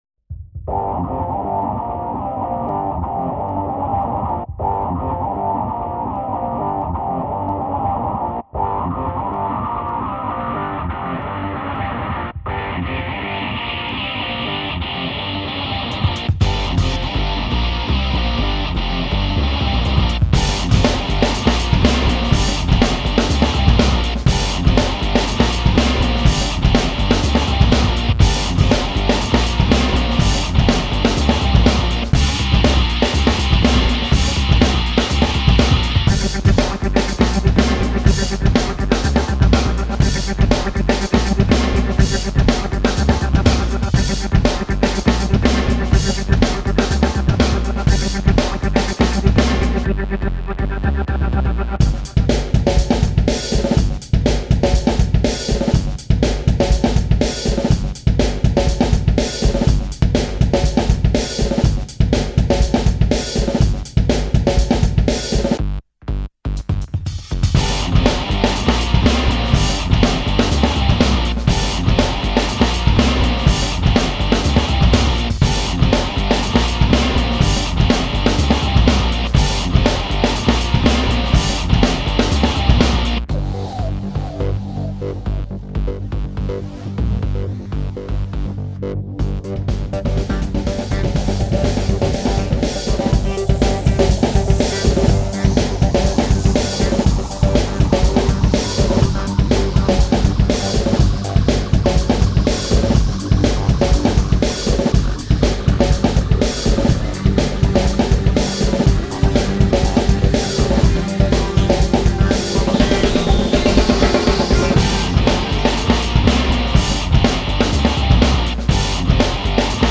Electronic/Dance
Der neue BigBeat/Crossover Act aus München.